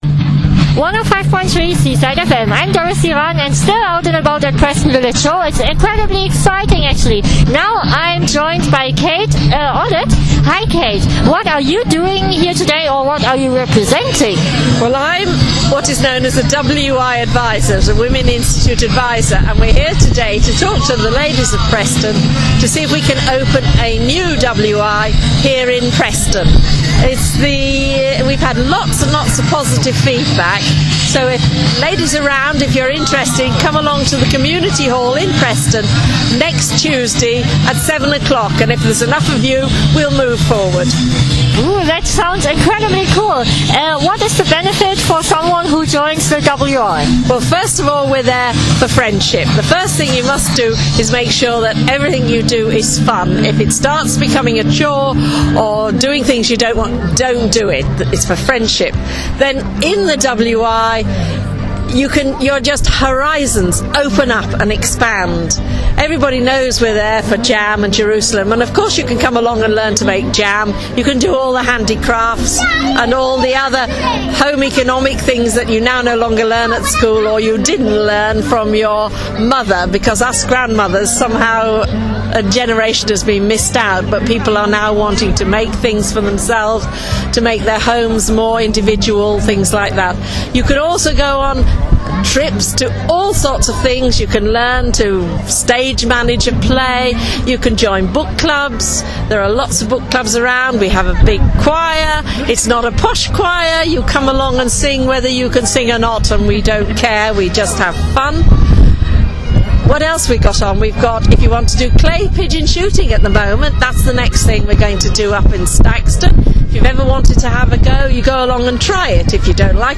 WI Interviewed At Preston Village Show